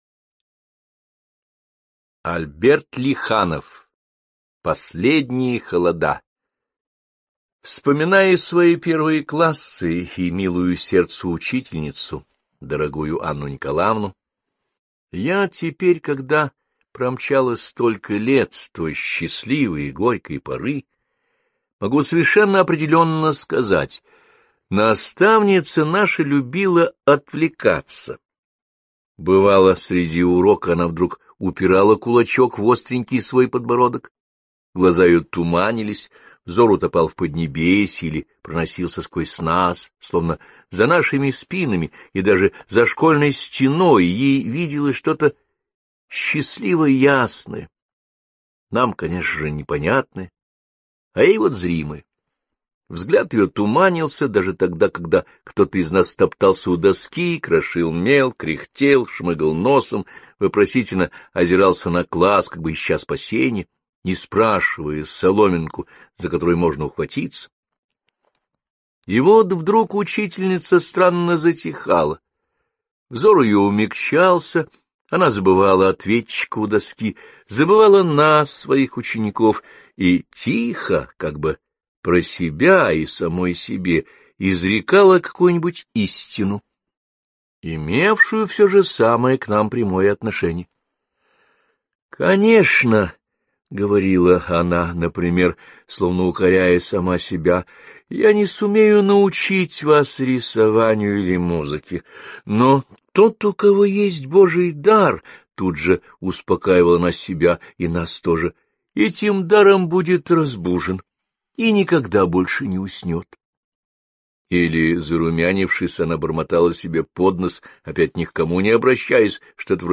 Последние холода - аудио повесть Лиханова - слушать онлайн